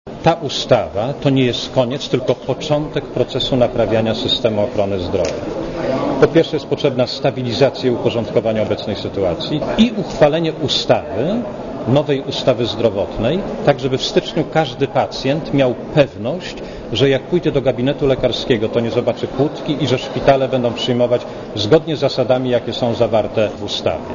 Również minister zdrowia potwierdza, że posłowie zdążą z pracami nad ustawą. Marek Balicki dodaje jednak, że ustawa, która ma wejść w życie jesienią to dopiero początek naprawy służby zdrowia.